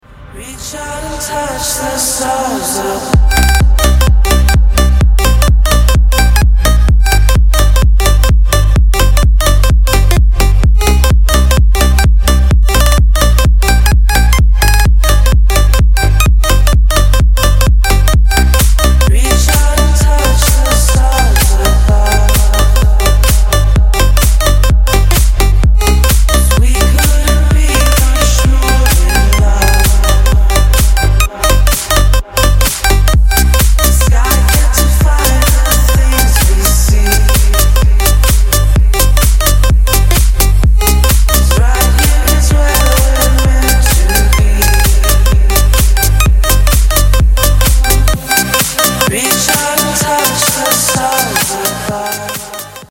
dance
club
house